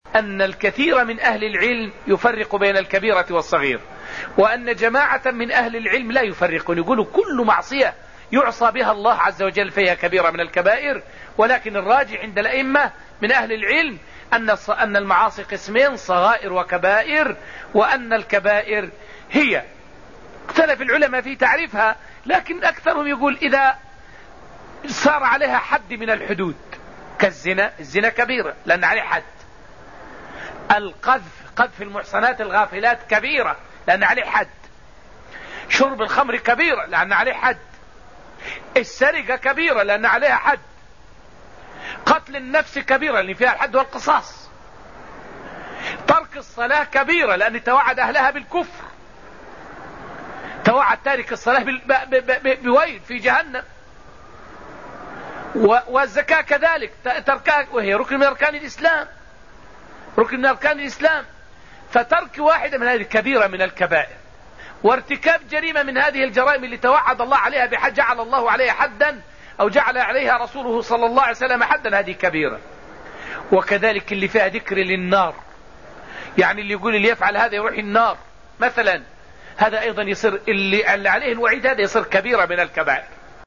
فائدة من الدرس الثاني عشر من دروس تفسير سورة النجم والتي ألقيت في المسجد النبوي الشريف حول هل هناك فرق بين الصغائر والكبائر؟